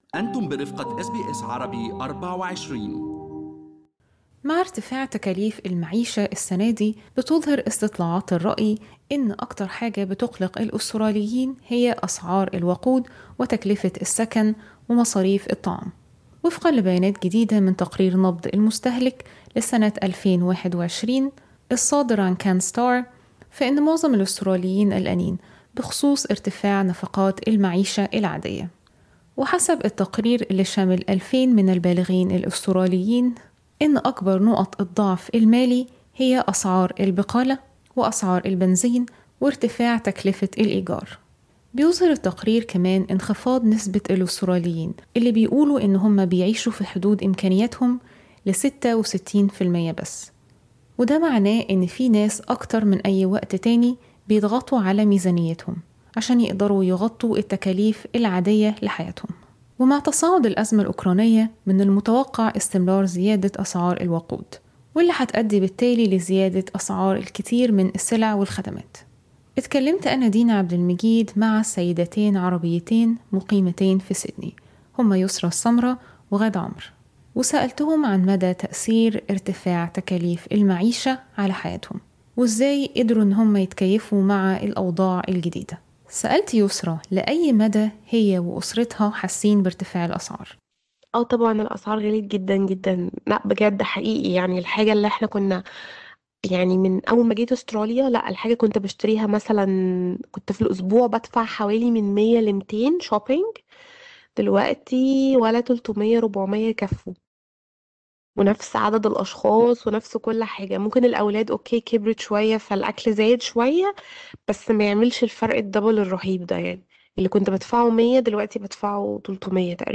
rising_prices_interviews_web.mp3